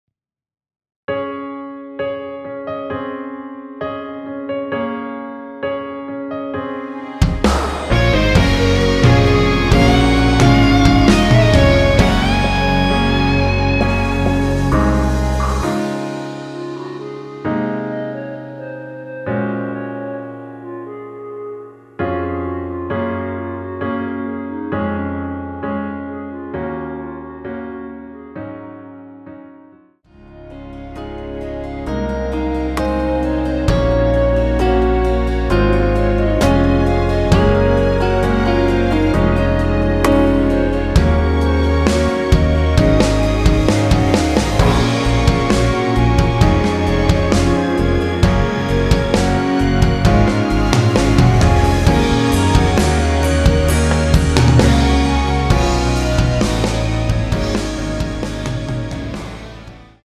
원키 (1절앞+후렴)으로 진행되는멜로디 포함된 MR입니다.(미리듣기 확인)
Db
앞부분30초, 뒷부분30초씩 편집해서 올려 드리고 있습니다.
중간에 음이 끈어지고 다시 나오는 이유는